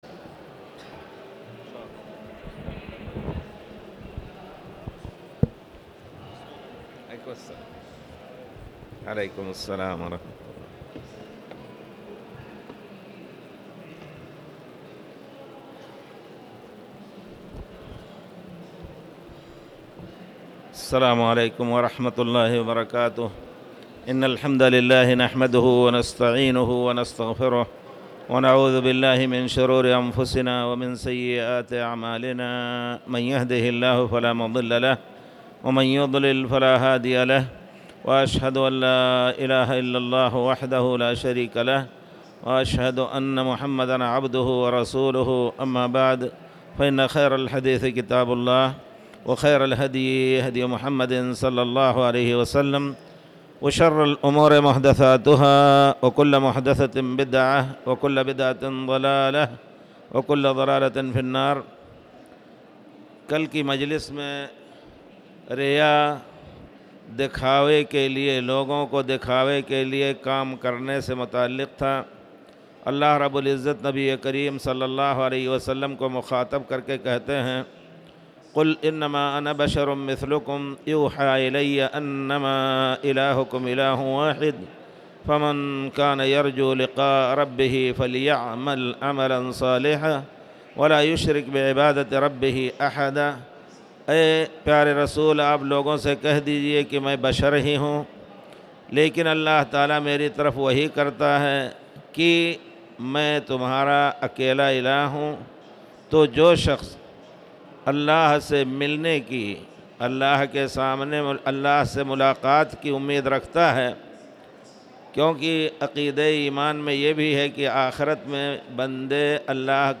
تاريخ النشر ١٨ ذو الحجة ١٤٣٨ هـ المكان: المسجد الحرام الشيخ